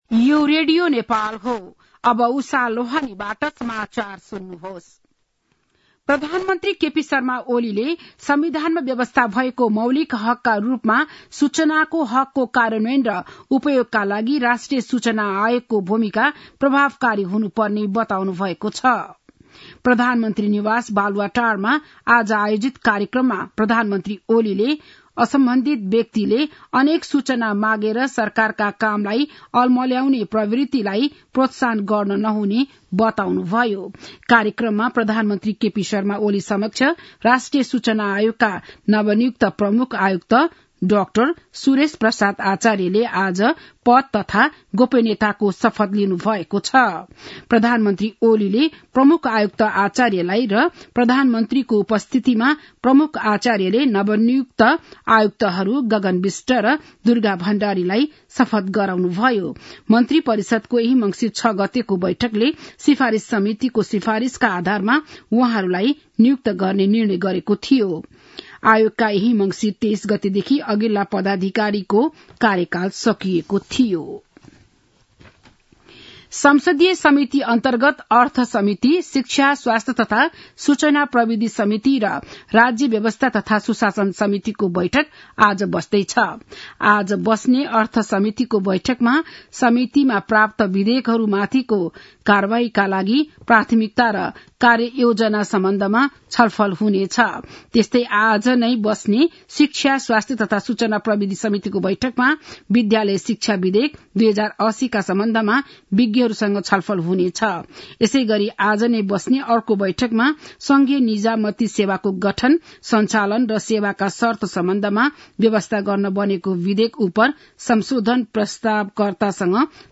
बिहान ११ बजेको नेपाली समाचार : २७ मंसिर , २०८१
11-am-nepali-news-1-9.mp3